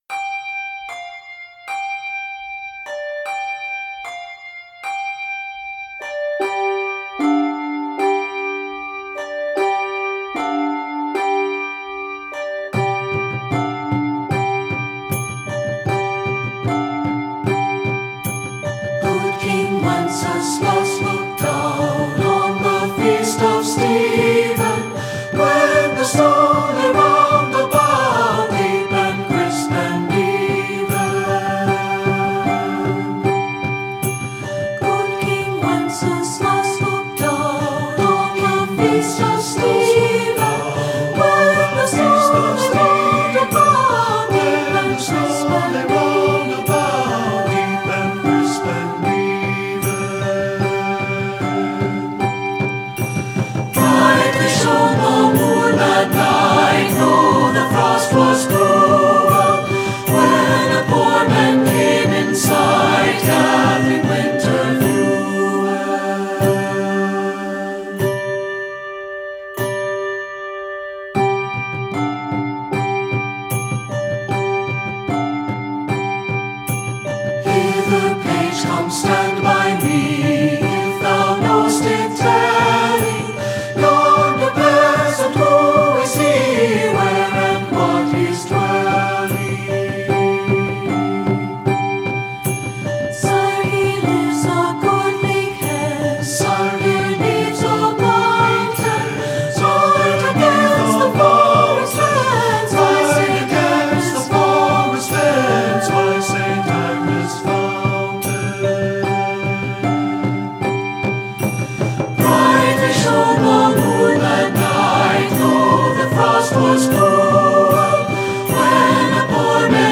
Voicing: 3-Part Mixed and Piano